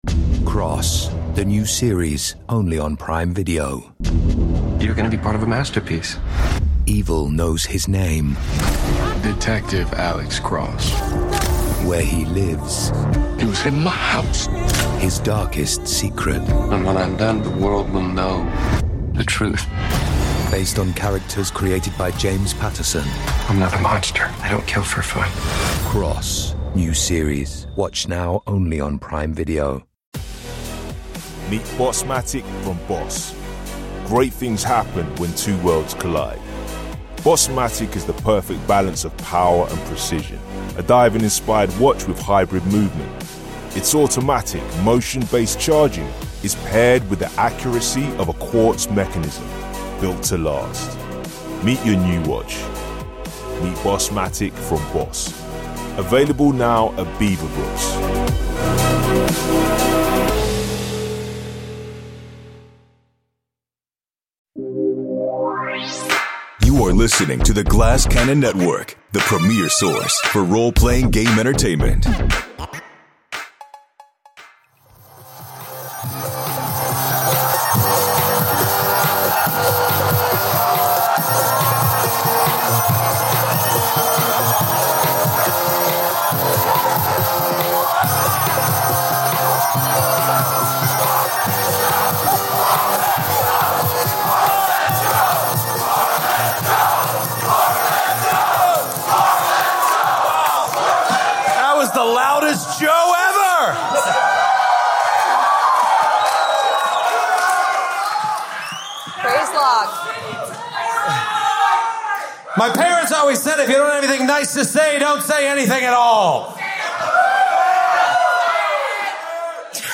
The Portland crowd was on fire for an intense series of battles as the heroes edge ever closer to the Soul of the Mysterium.